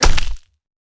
bullet_hit_npc.wav